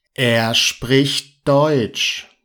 แอร์-ชพริค(ชท)-ด็อย(ทช)